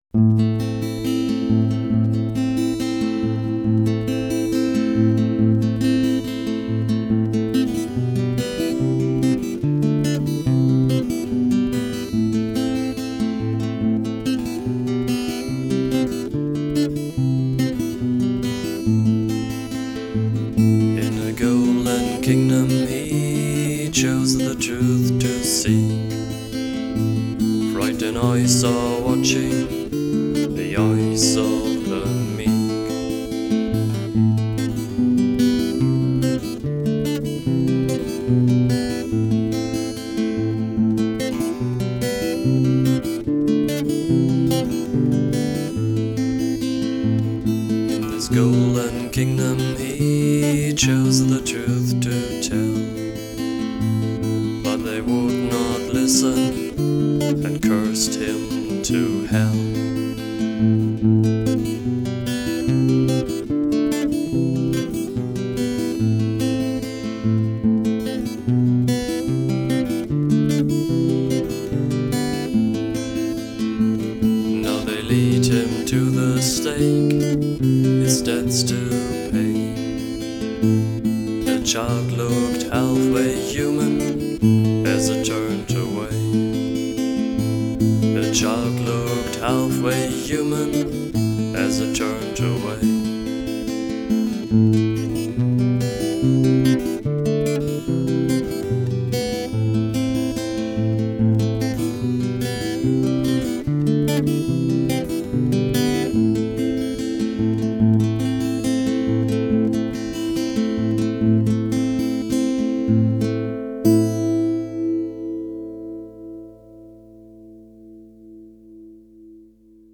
(Live)
Стиль: Dark Folk